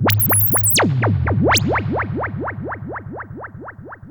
Theremin_FX_18.wav